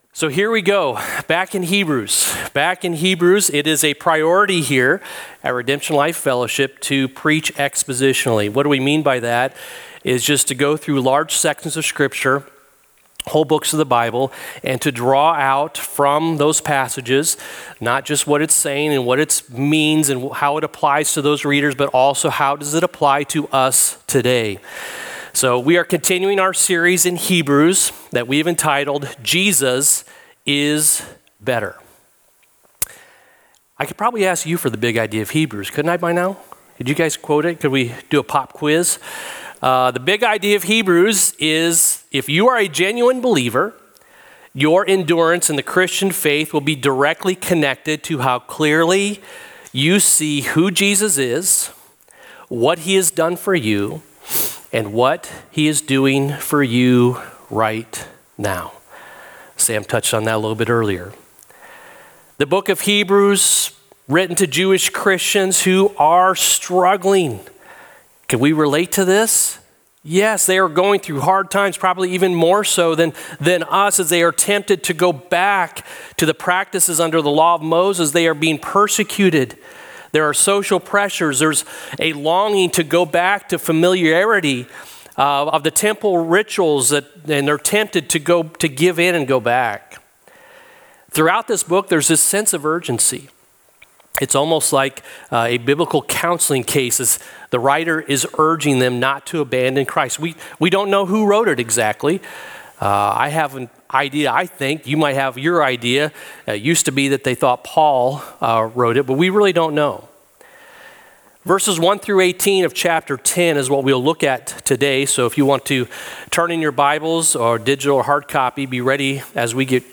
Downloads & Resources Audio Sermon Notes (PDF)